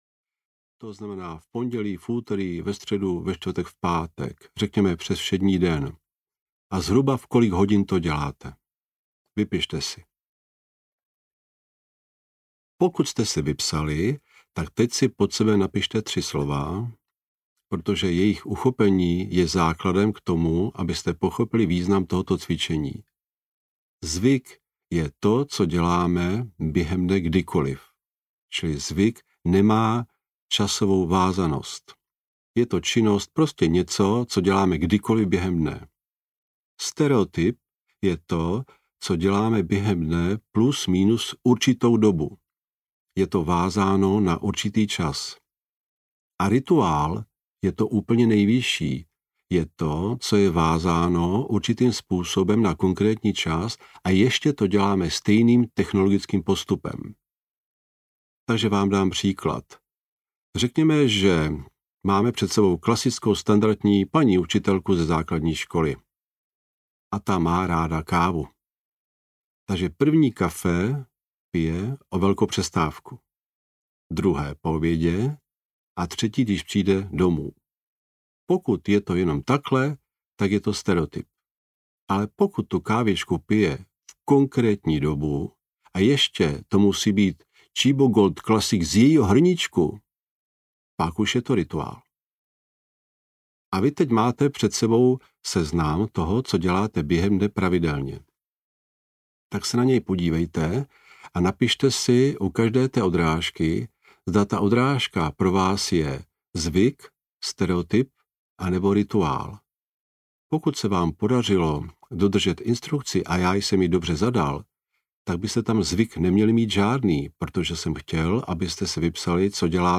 Jak omezit vtíravé myšlenky a zlepšit kvalitu života audiokniha
Ukázka z knihy